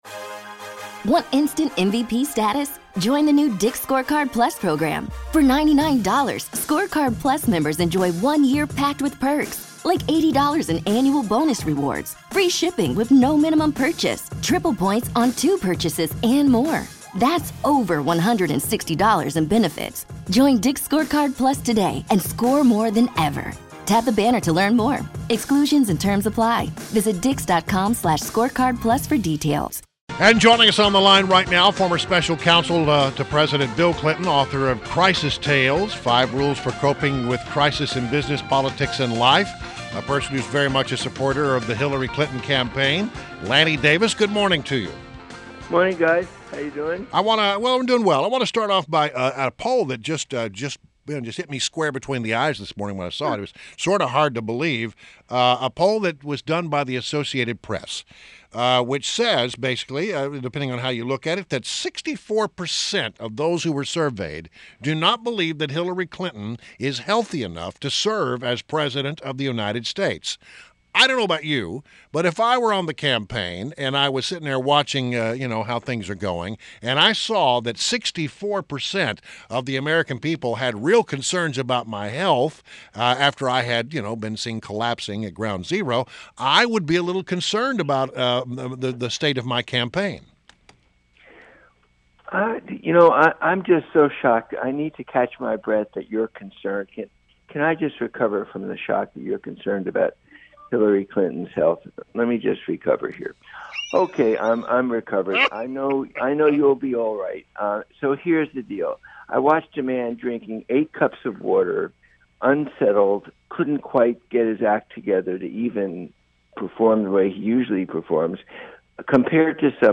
WMAL Interview - LANNY DAVIS - 09.28.16